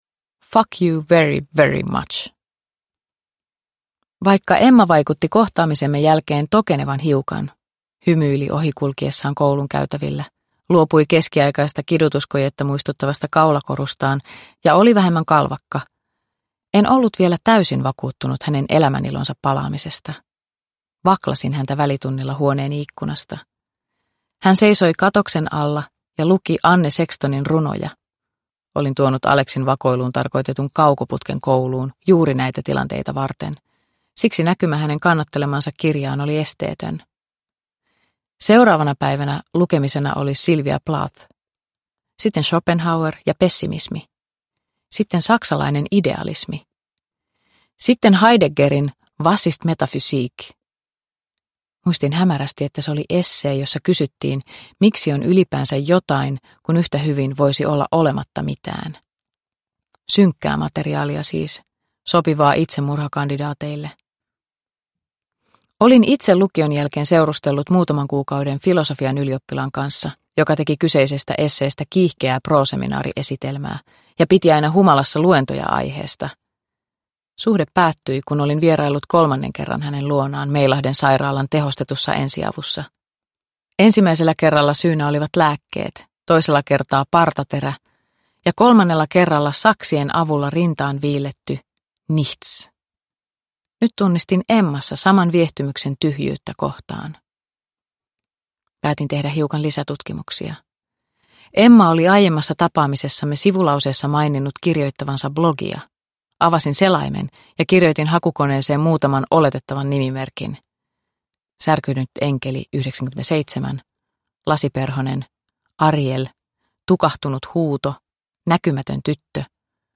Iiris Lempivaaran levoton ja painava sydän – Ljudbok – Laddas ner